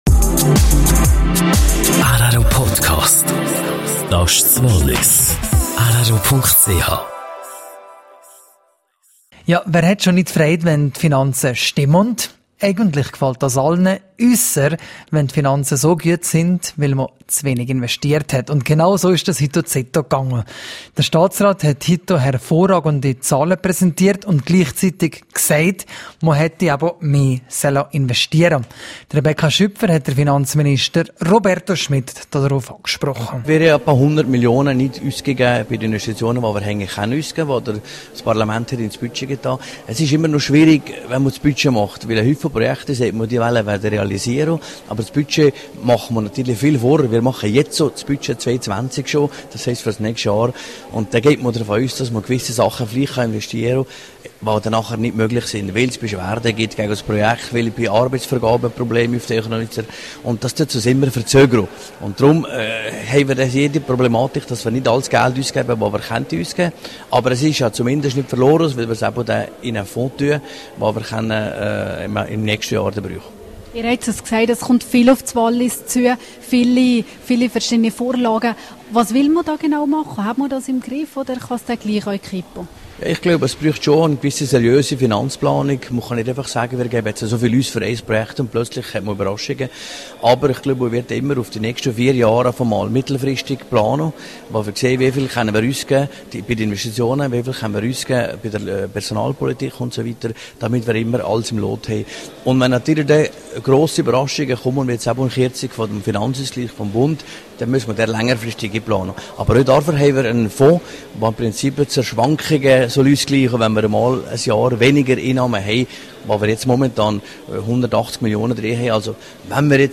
Neues Jagdgesetz: Interview mit Ständerat Beat Rieder.